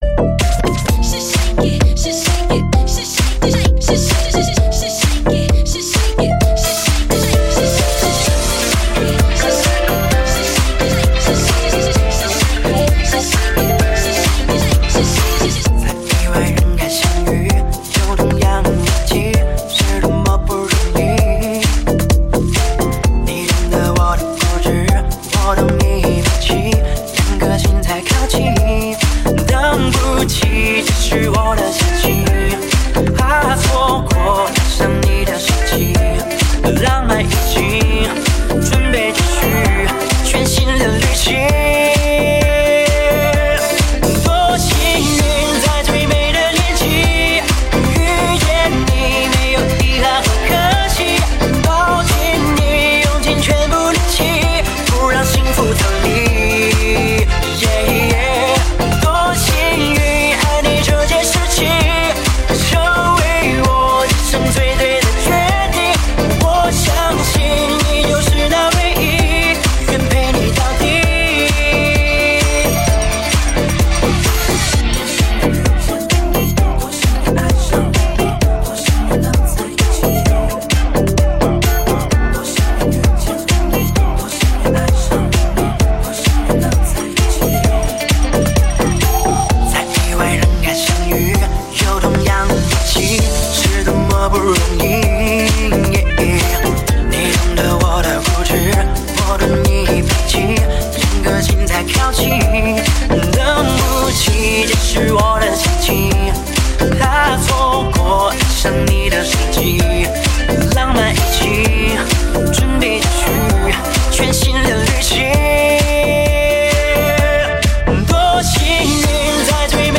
本铃声大小为1794.1KB，总时长207秒，属于DJ分类。